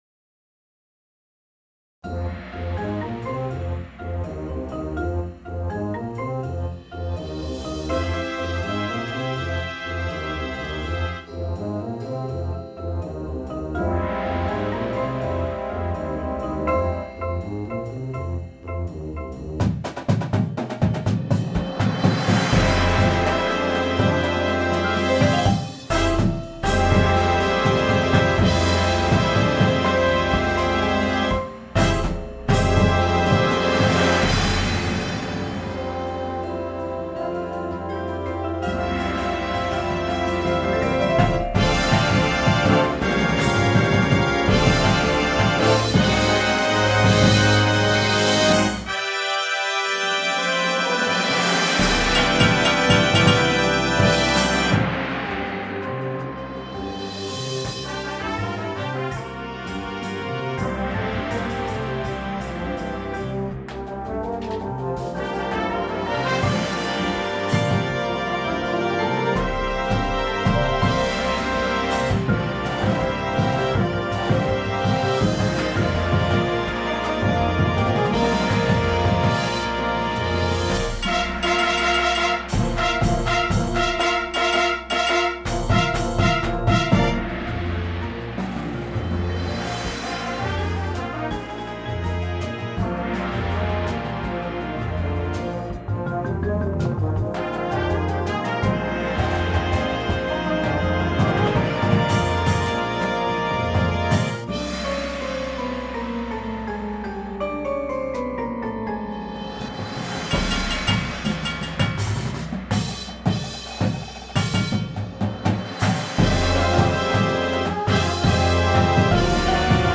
• Flute
• Trombone 1, 2
• Tuba
• Snare Drum
• Bass Drums